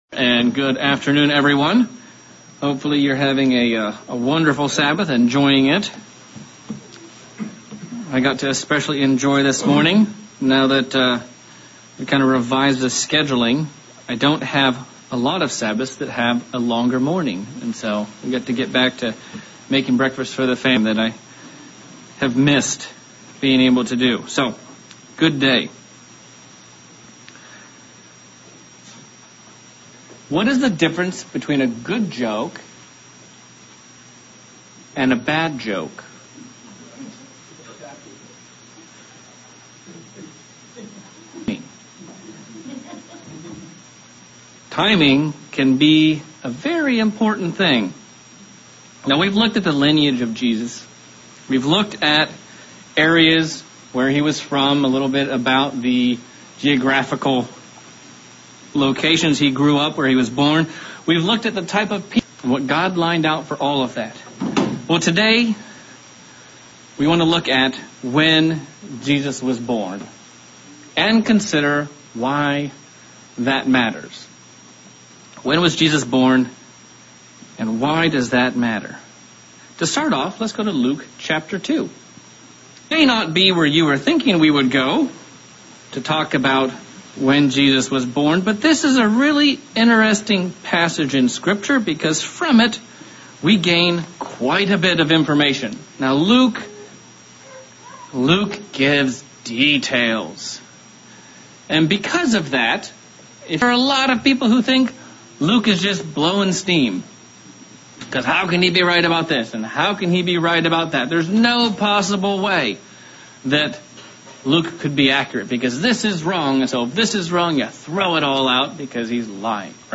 Sermon continuing our series of When Was Christ born. This sermon focuses on the prophecies and timings of when that would actually occur.